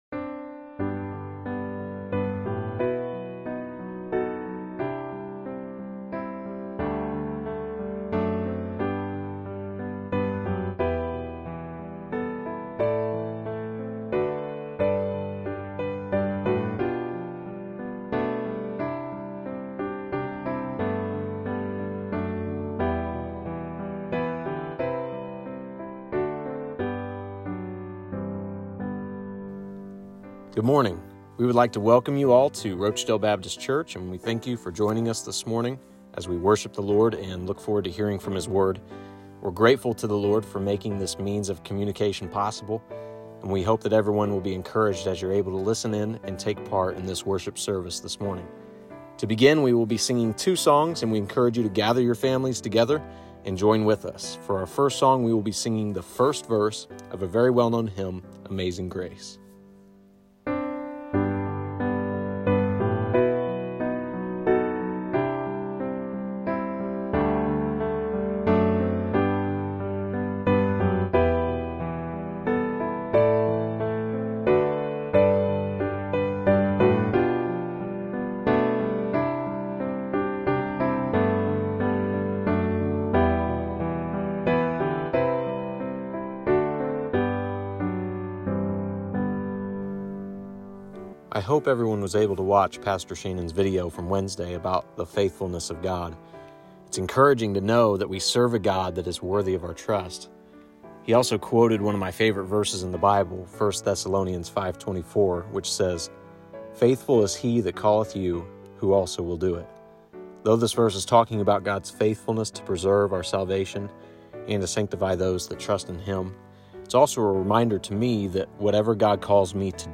Hymns
Hymns In the audio clip we included the following songs.